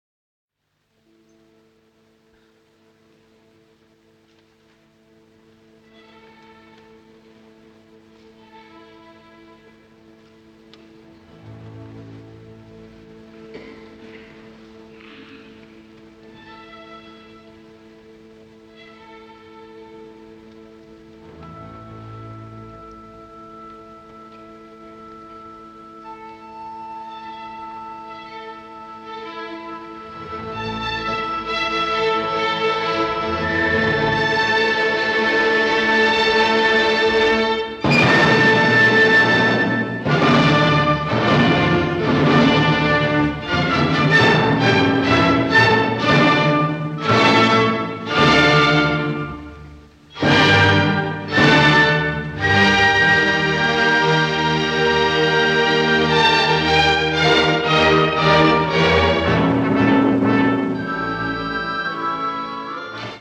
At times, Furtwängler has the orchestra blasting pell-mell through the music.
The flutes scream, the kettle-drums sound like artillery, and when the music spirals at 1:30 in the Allegro, it’s like a bird being shot out of the air.
Here’s a bit of the Furtwängler version from 1942:
It’s possible that the wire reel recording or the vintage microphone lost a lot of the bass from the recording.
One of them complains that “there is too much background noise and coughing.”